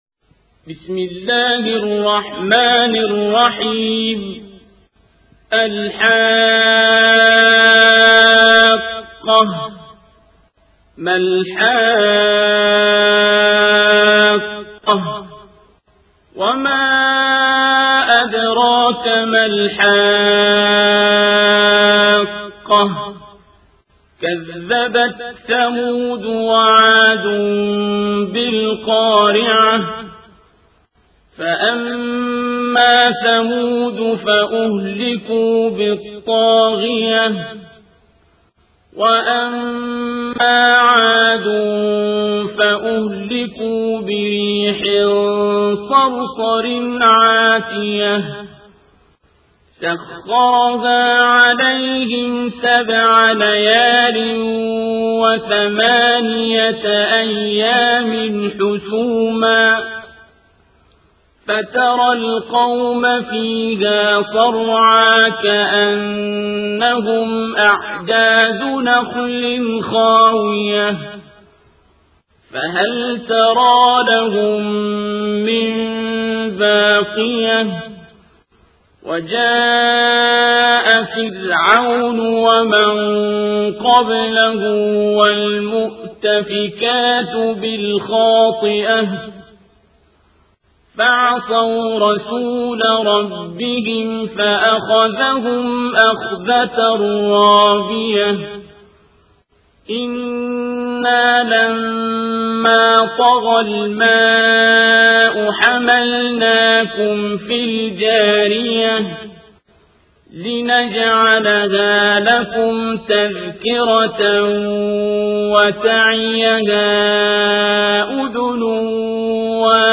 القارئ: الشيخ عبدالباسط عبدالصمد